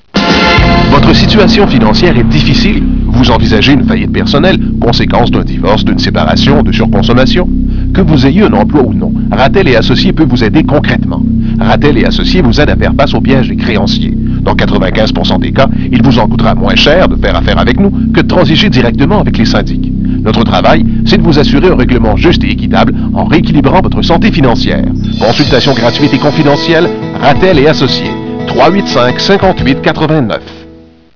Cliquer sur un des 2 liens pour entendre le fichier ".wav" de nos annonces diffusées à CKMF.